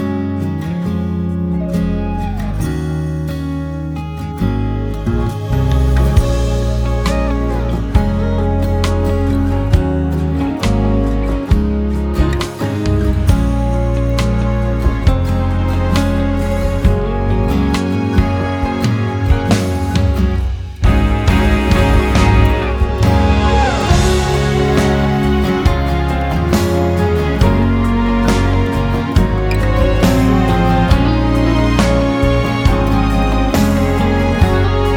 Жанр: Поп музыка
K-Pop, Pop